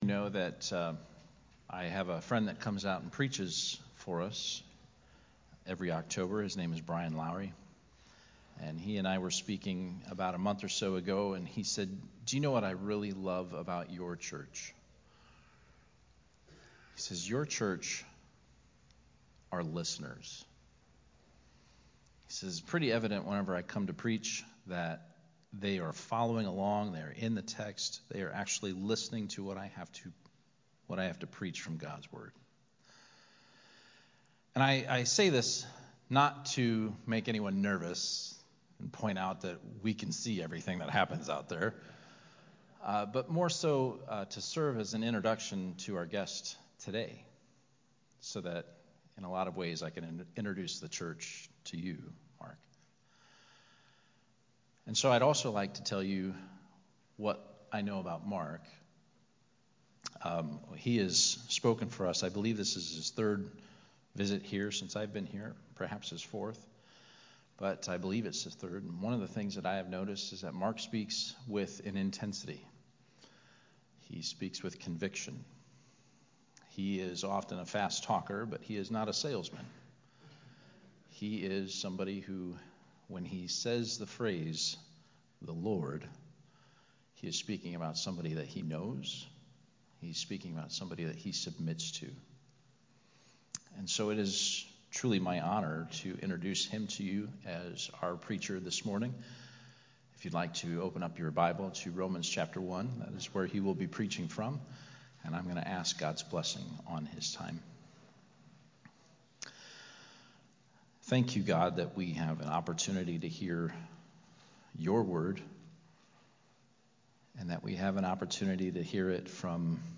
Romans 1:16, A Missionary Sermon